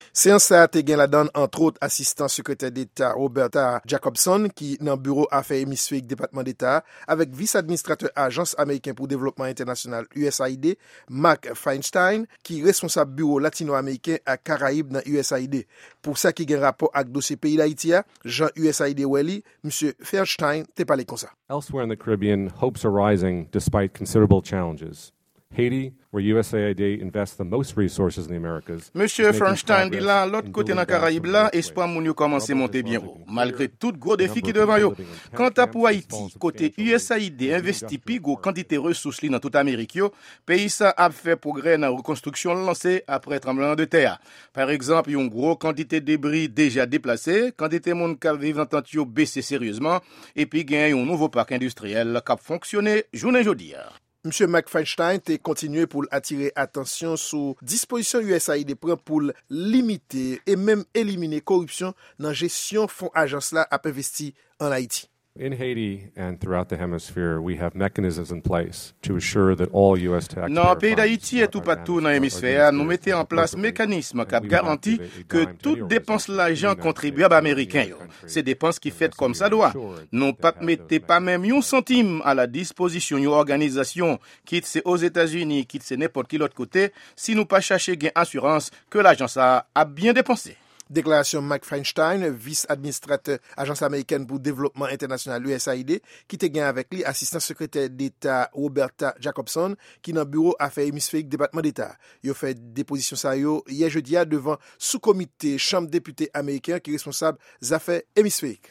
Repòtaj Sèvis Kreyòl Lavwadlamerik la